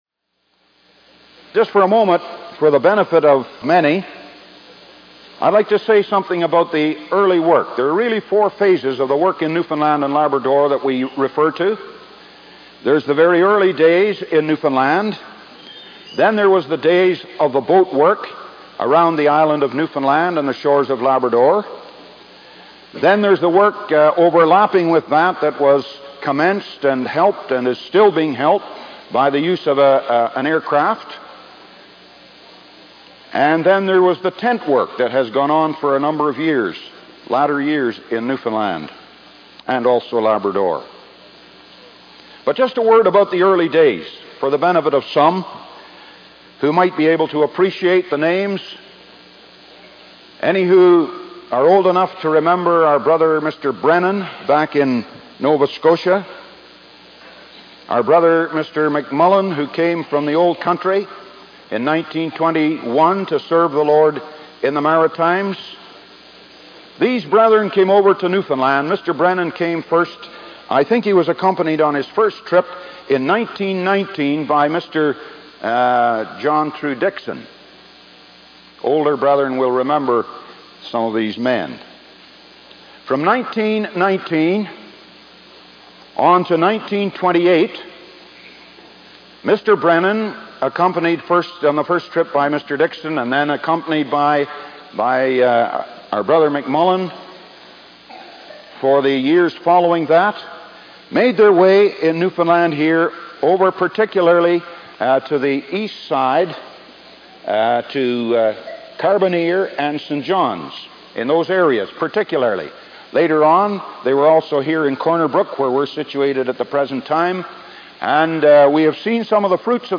Missionary Reports